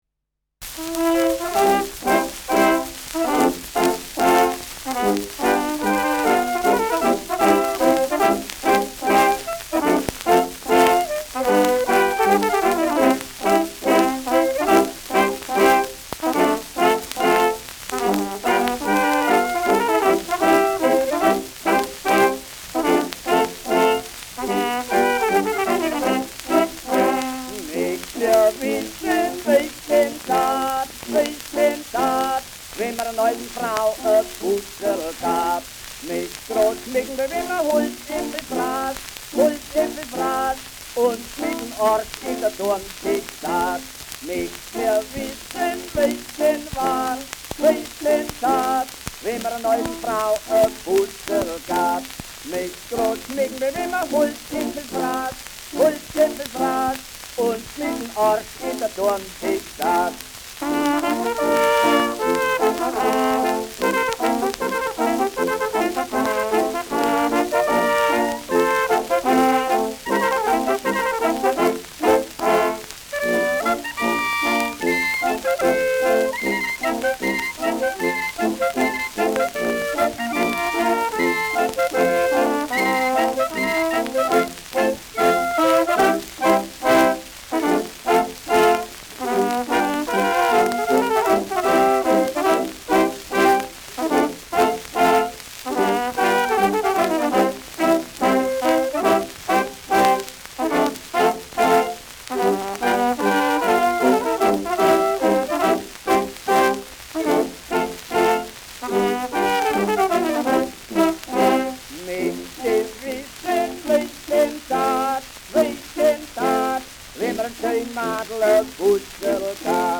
Polka mit Gesang
Schellackplatte